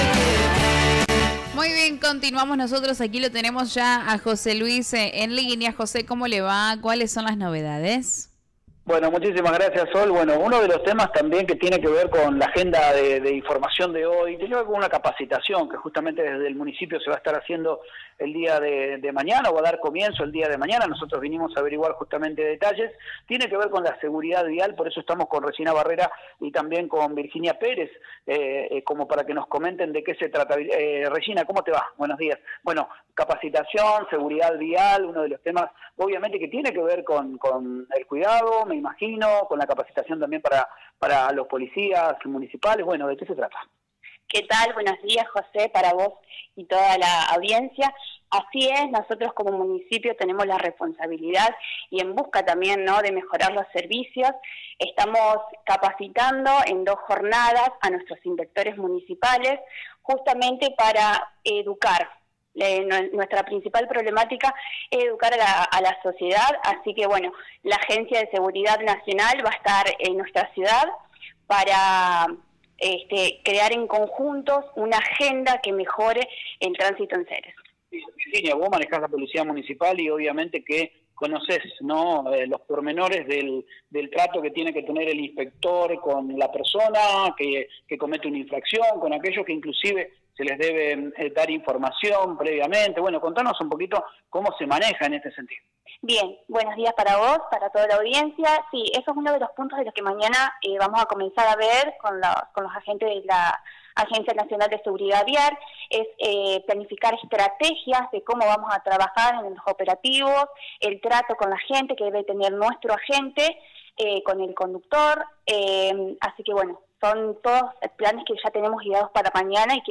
En la entrevista las Funcionarias Municipales dieron los detalles de la capacitación e indicaron que será durante dos días, mañana y tarde, con teoría y práctica.
Escucha la Palabra de la secretaria de Gobierno Regina Barrera y la directora de la Policía Municipal Virginia Pérez.